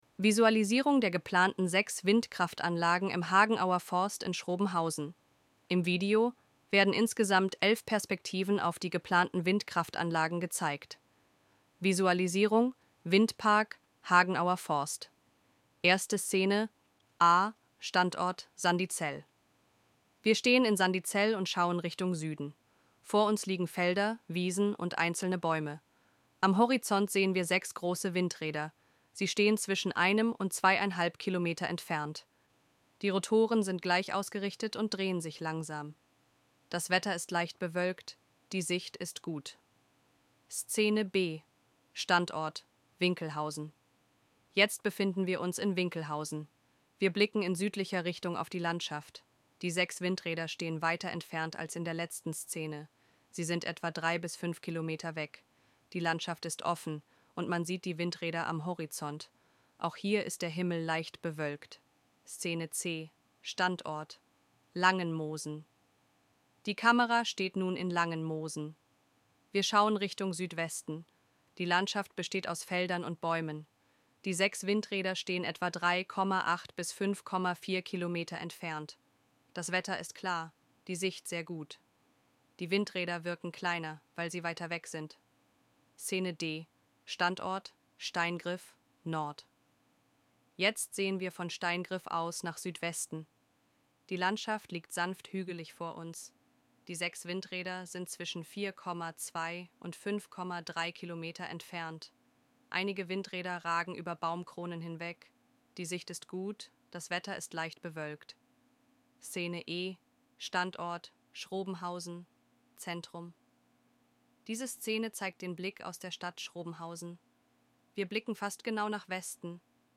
Audiodeskription zum Video Visualisierung von Windkraftanlagen: